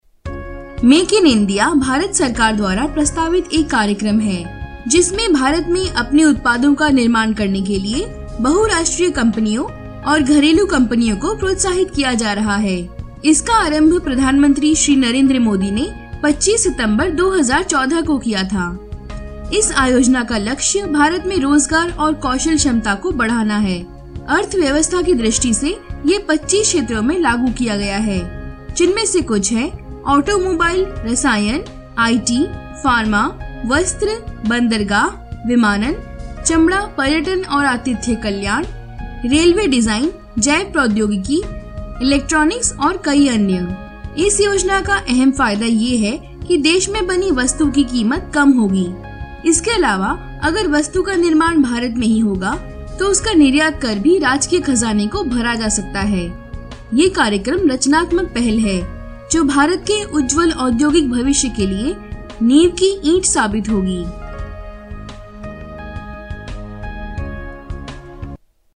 印地语青年娓娓道来 、积极向上 、素人 、女课件PPT 、工程介绍 、绘本故事 、动漫动画游戏影视 、40元/百单词女印01 印度印地语女声 年轻活泼 娓娓道来|积极向上|素人